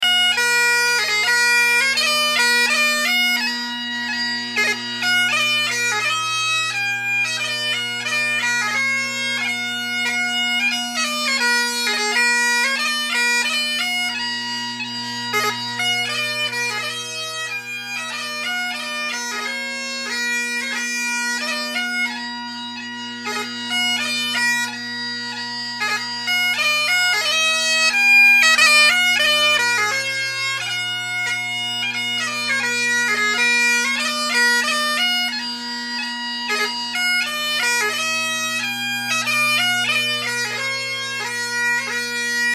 The top hand F, high G, and high A all sound very good. D is spot on, as is B. The other notes are a given.